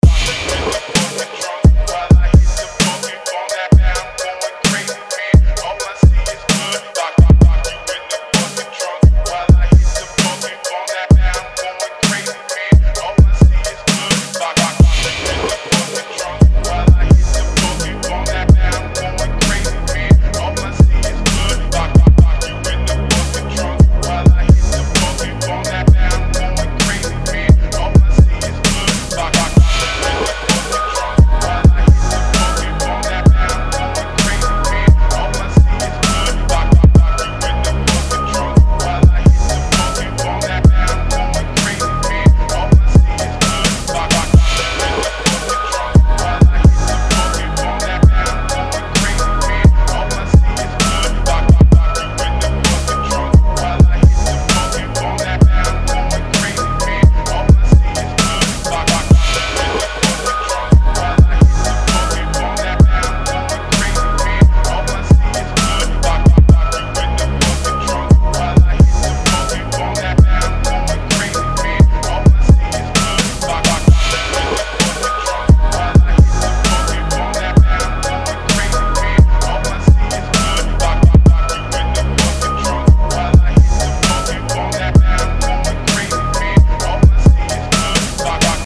Get that huge reese bass sound!
Phonk Bass Audio Demo
Phonk-Bass-Promo-Audio-Demo.mp3